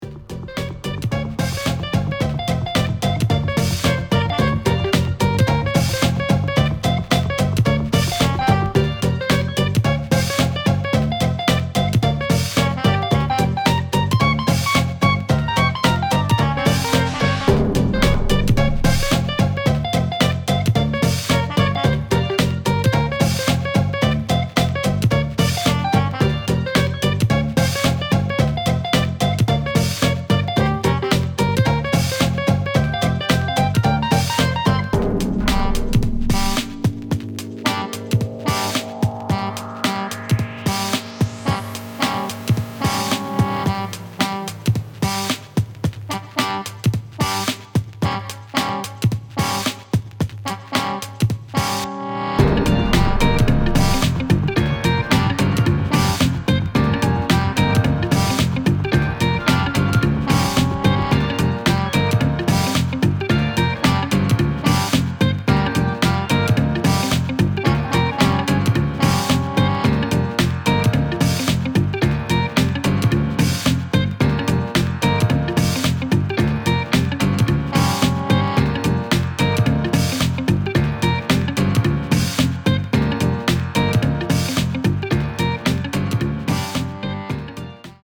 Acid jazz \ trip-hop \ breaks (демка)
Ускоренный трип-хоп с намеком на эйсид джаз. Ударка срезана не знаю откуда (из какого-то старого фанкового трека),нашел в библиотеке для контакта,остальное все сам дописывал.